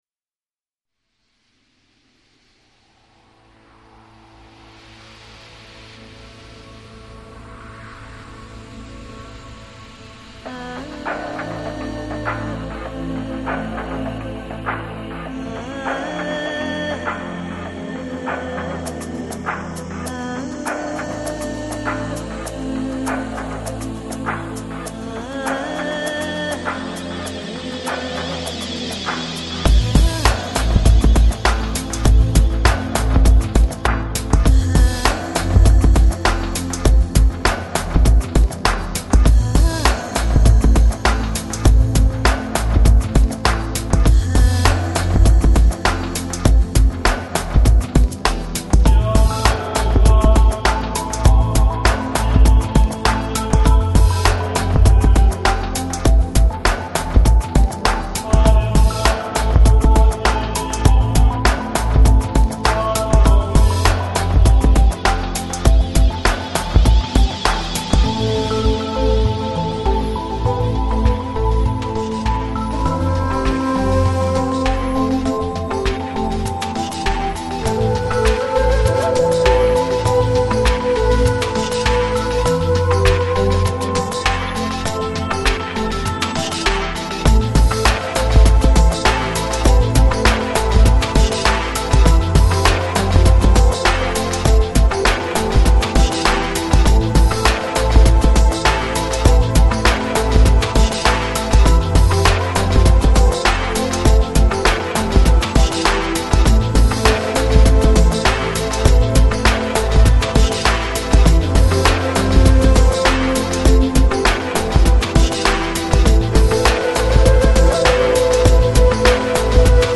Жанр: Balearic, Downtempo, Lounge